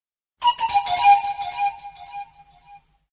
Рингтоны на СМС